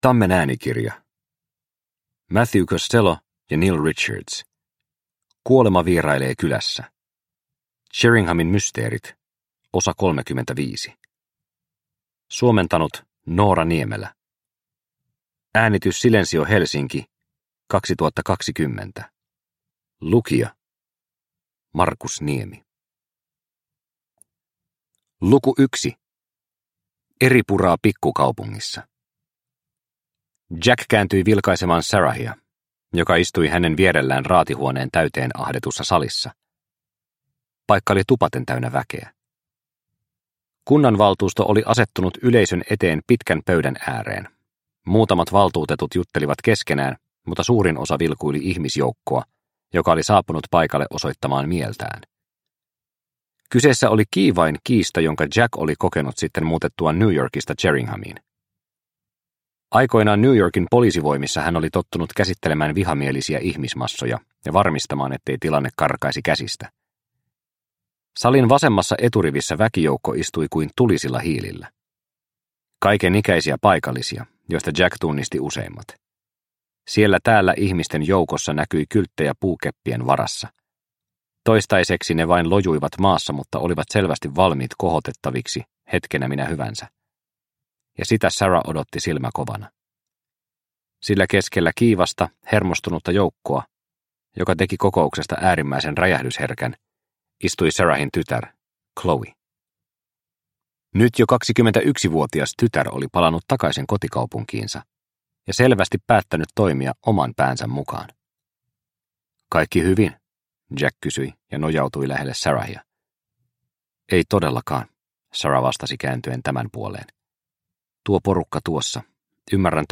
Kuolema vierailee kylässä – Ljudbok – Laddas ner